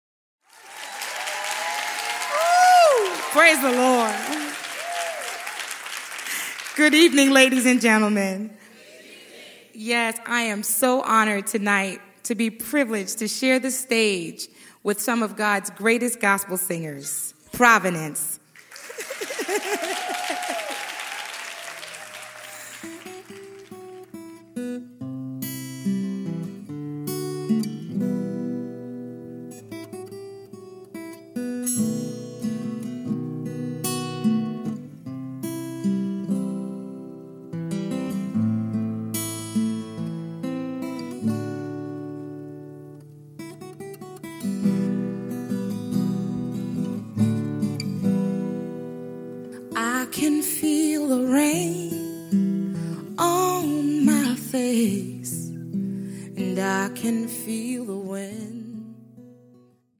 Live at the South Orange Performing Arts Center.